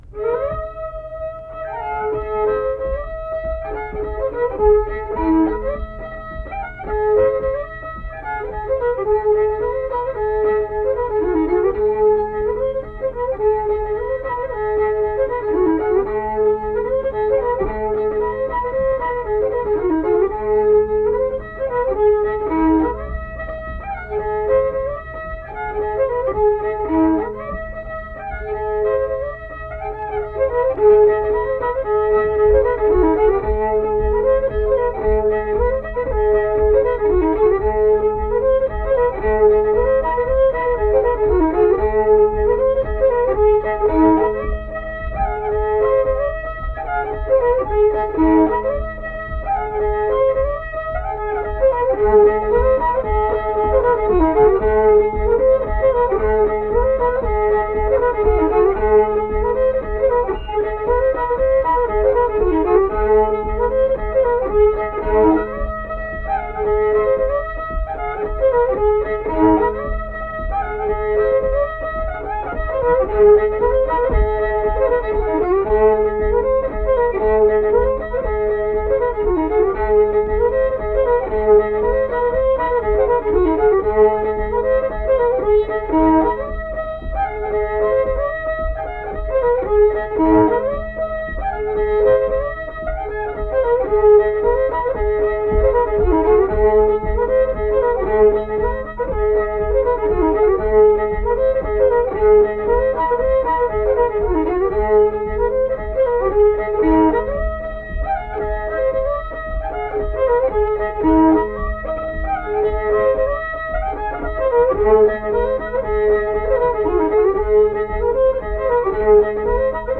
Accompanied guitar and unaccompanied fiddle music performance
Instrumental Music
Fiddle